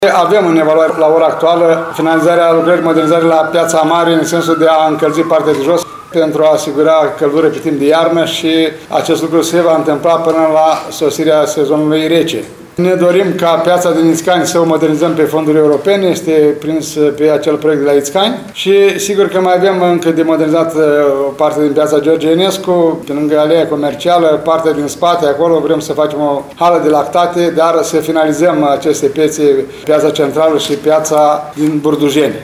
Primarul ION LUNGU a declarat, astăzi, că alte 3 piețe din municipiul Suceava vor fi modernizate.